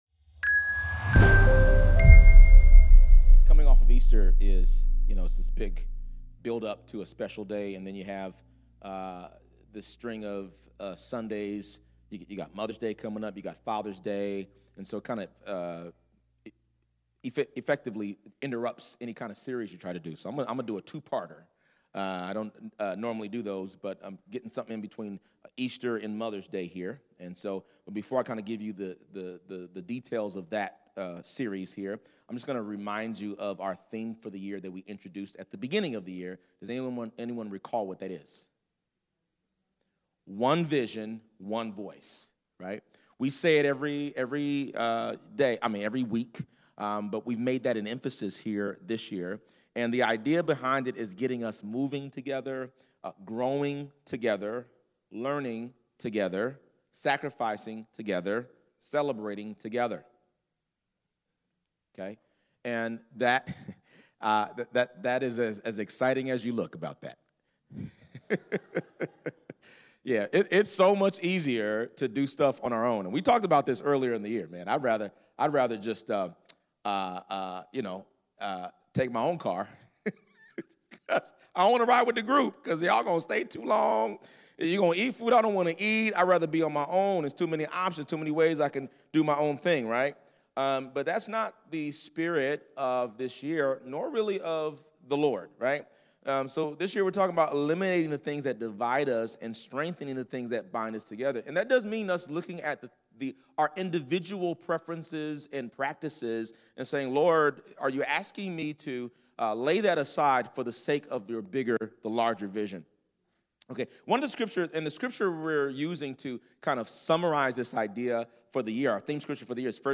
WATCH THE SERMON ONLY Listen to Our Worship Playlist FULL SERVICES SUNDAY SERMONS View Sermon Notes SONGS WE SING IN SERVICE LISTEN TO THE FULL TRACKS ON SPOTIFY LISTEN TO THE FULL TRACKS ON YOUTUBE